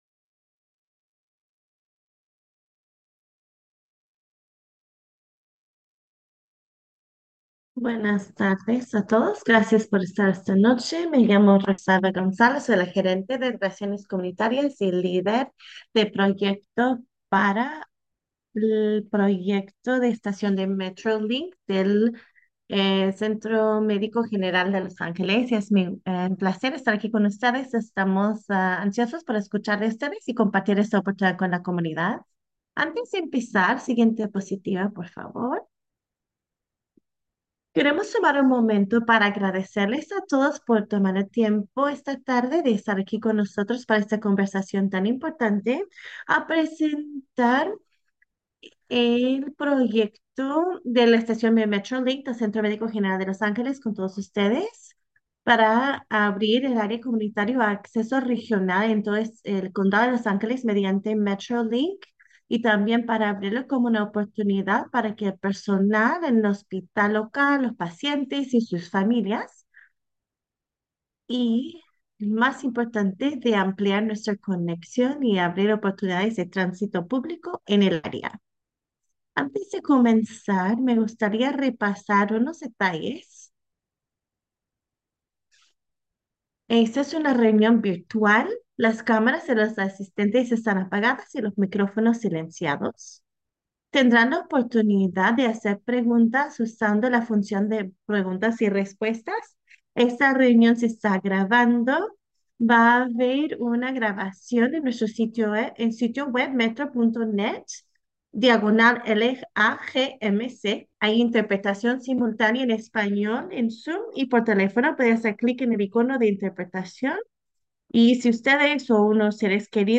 lagmc-1-27-26-community-meeting-spanish-interpretation-audio-only.m4a